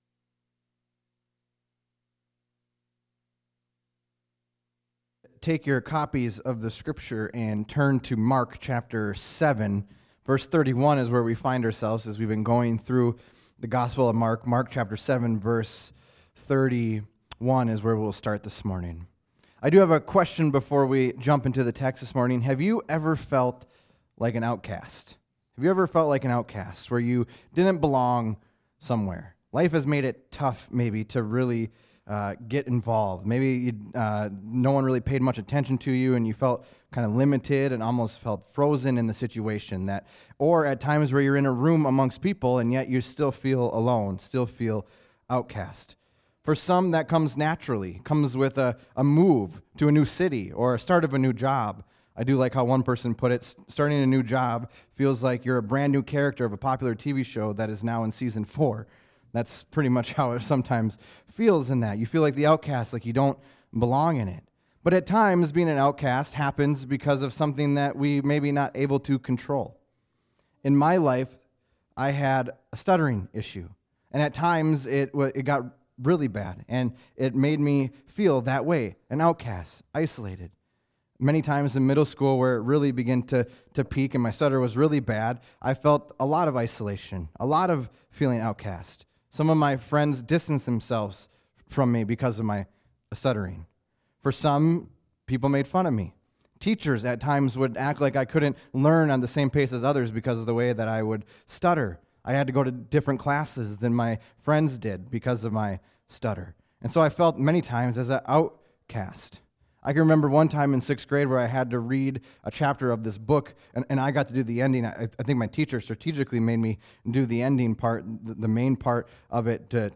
240324sermon.mp3